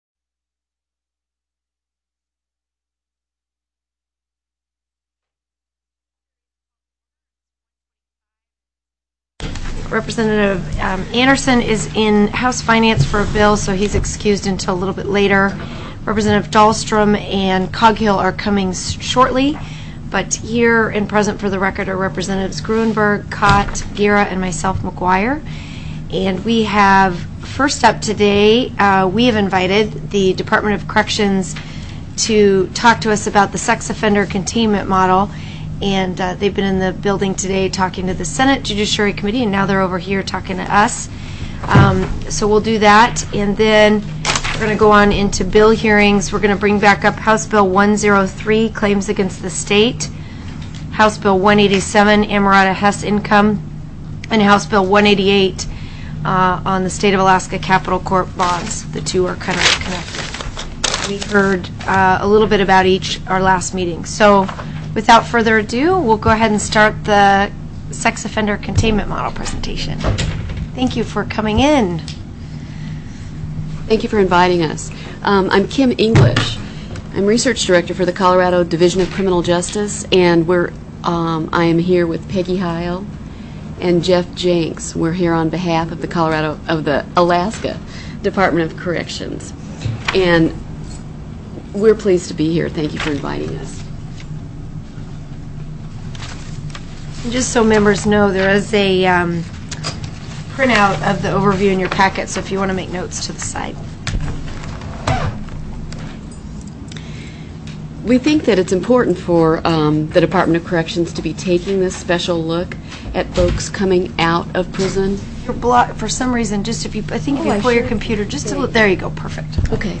03/16/2005 01:00 PM House JUDICIARY
TELECONFERENCED